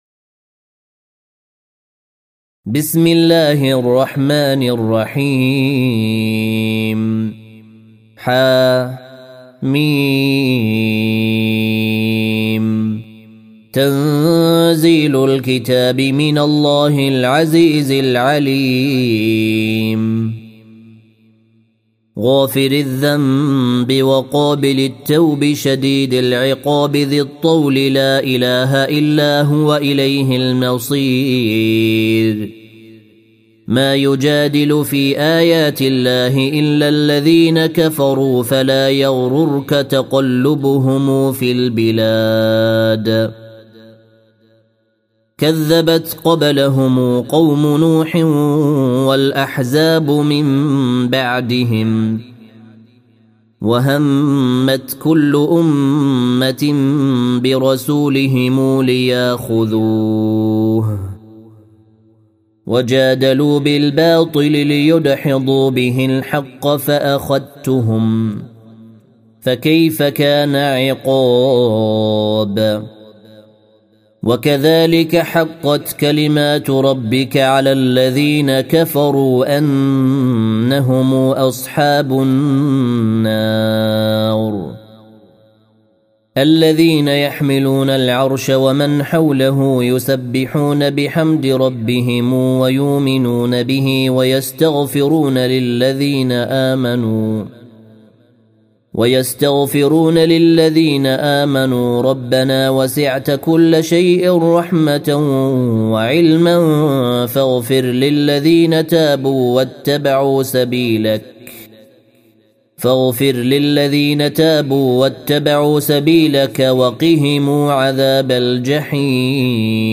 جودة عالية